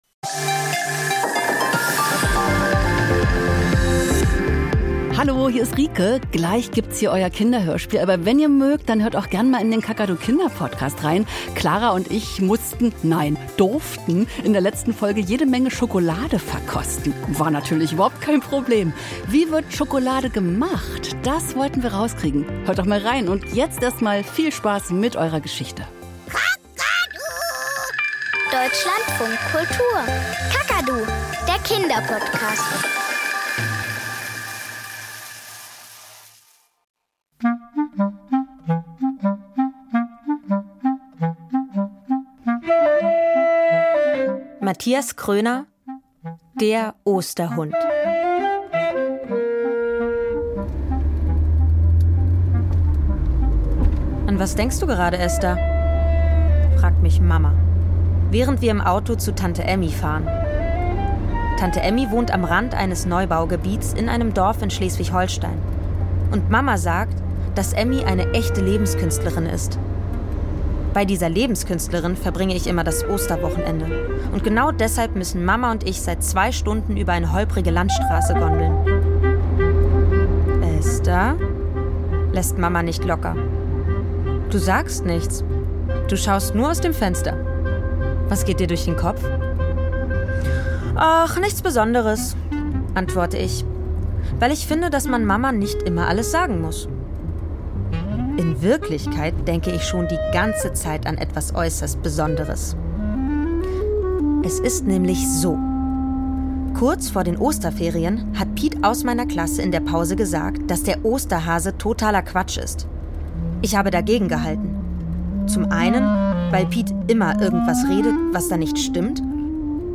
Kinderhörspiel und Geschichten - Der Osterhund